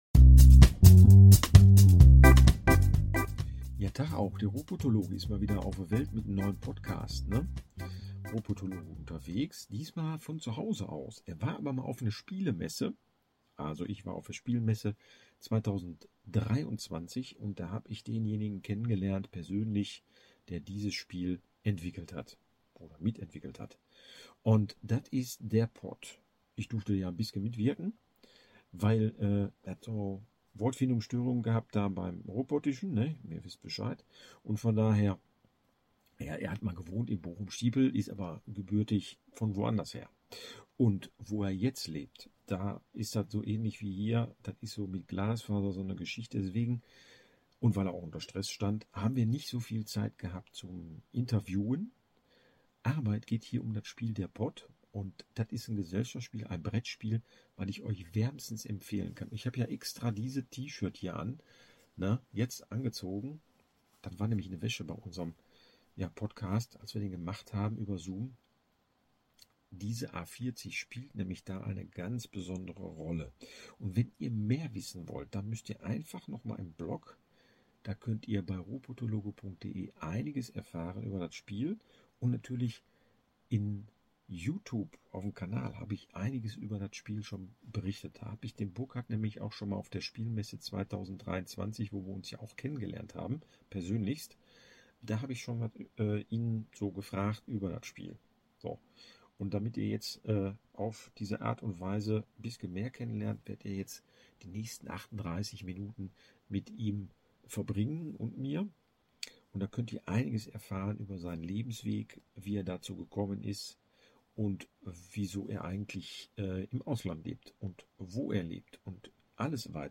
Drum lud ich ihn zu einer Zoom Konferenz ein, denn er lebt auf Sardinien.
Leider hatten wir dann auch technische Störungen und die kostenlose Variante geht nur 40 Minuten und so hackte der Podcast bei der Verabschiedung ab.